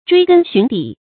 追根尋底 注音： ㄓㄨㄟ ㄍㄣ ㄒㄩㄣˊ ㄉㄧˇ 讀音讀法： 意思解釋： 見「追根究底」。